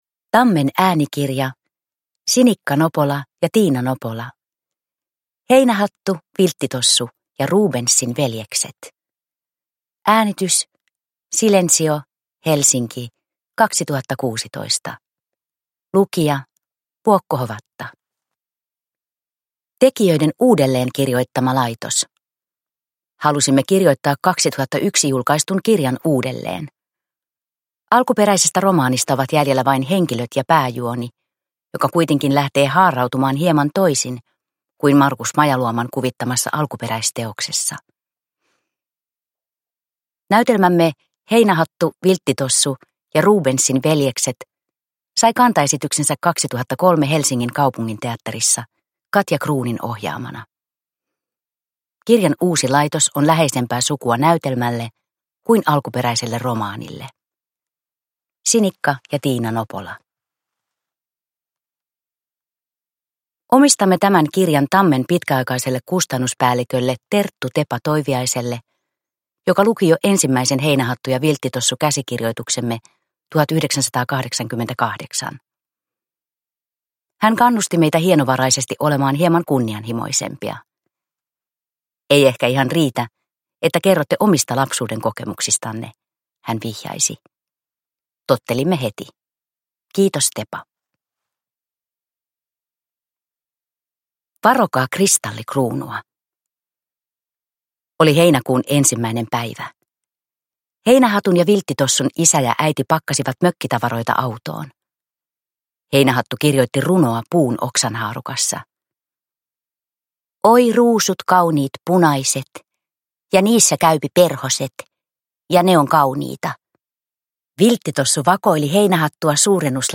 Heinähattu, Vilttitossu ja Rubensin veljekset – Ljudbok
Uppläsare: Vuokko Hovatta